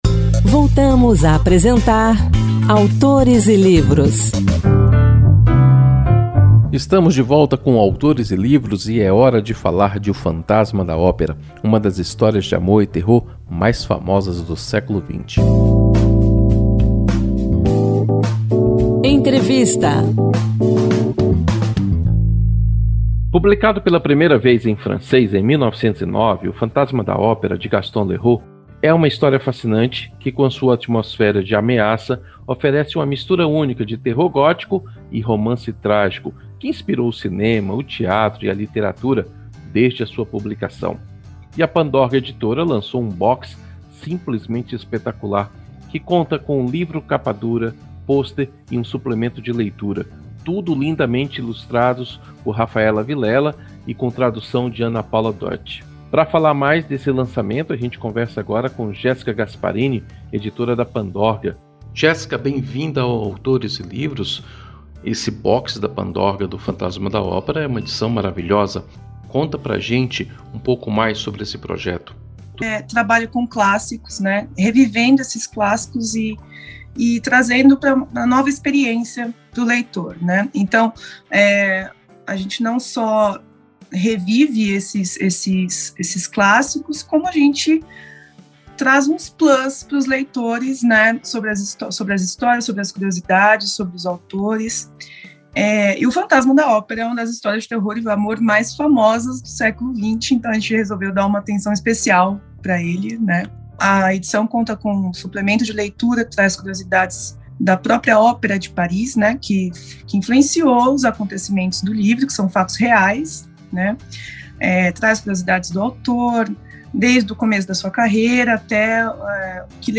E o Encantos de Versos faz uma homenagem ao Dia Mundial do Meio Ambiente, celebrado em 5 de junho, com versos de Paulo Bomfim, autor do poema “Cimento Armado”.